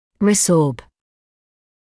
[rɪ’sɔːb][ри’соːб]резорбировать(ся)